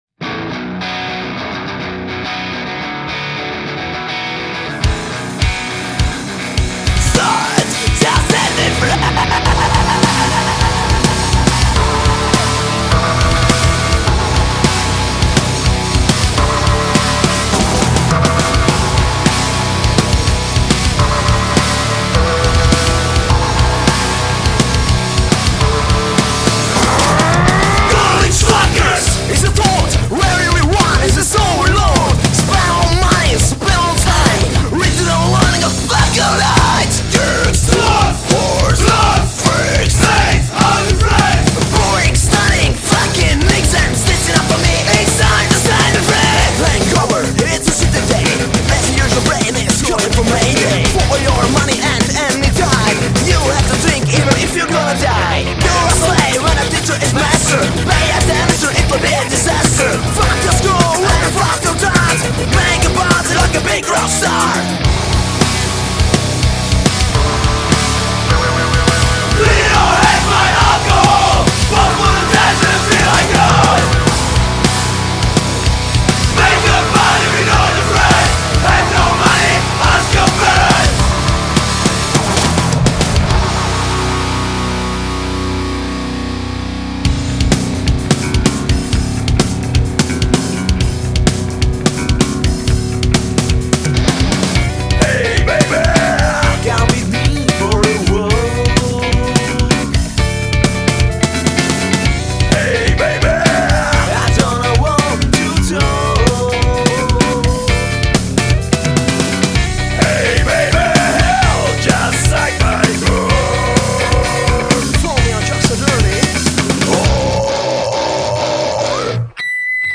Žánr: Metal/HC
Dance metal s prvky elektronické hudby a moderního metalu.